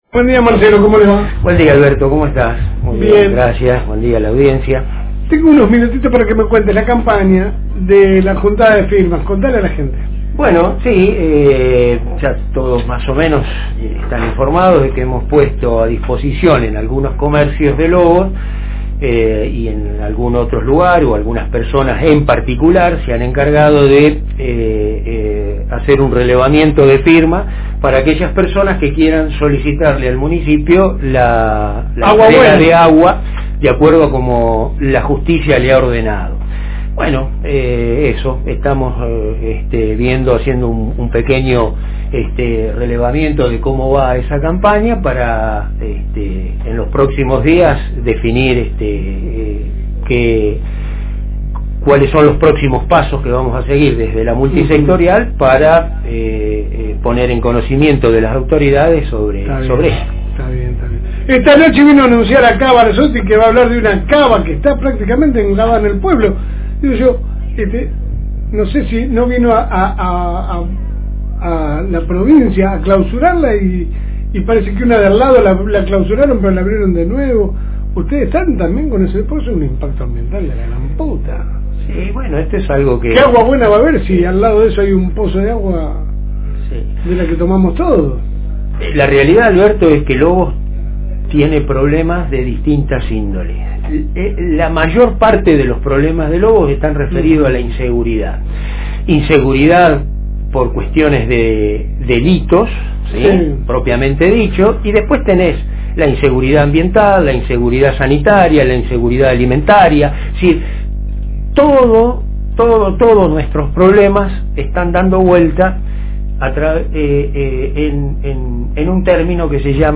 paso por los estudios de la Fm Reencuentro